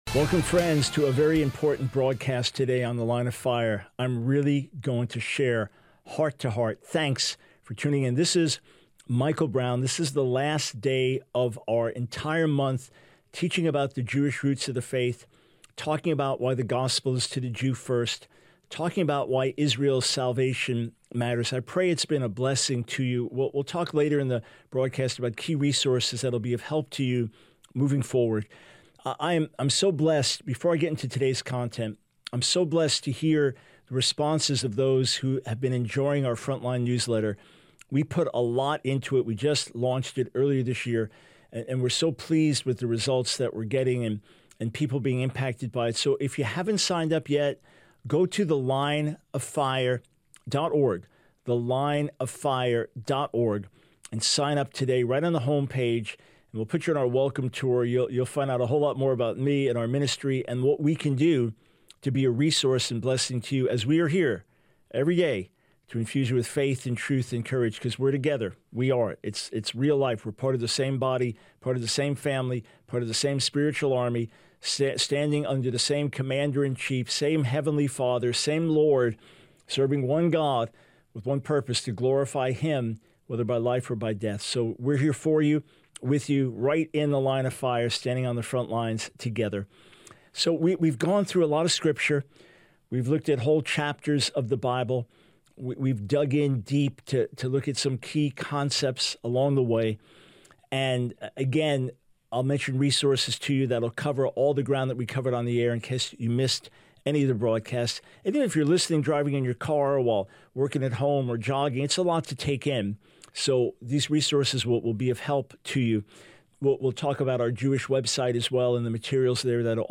Radio Broadcast from The Line of Fire